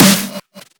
OPS_HPF3_DISTORTED_SNARE_2_G_150.wav